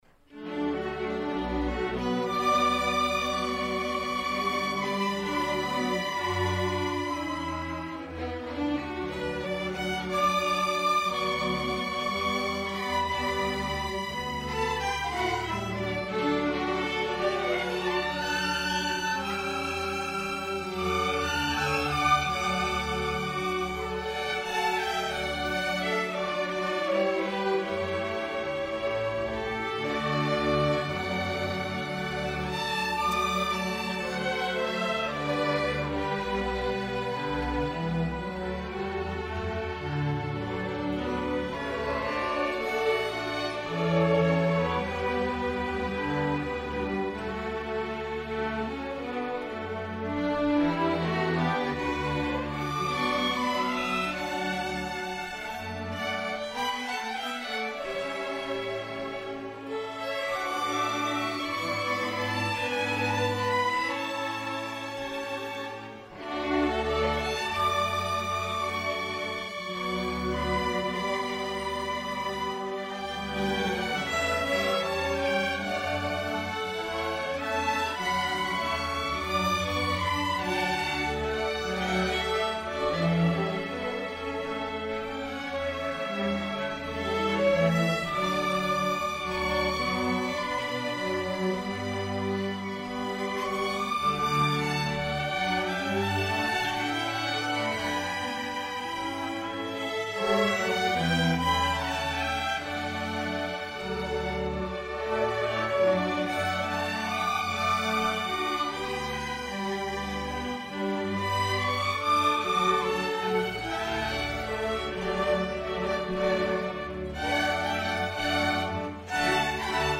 Spring 2017 Concert
Queen City Community Orchestra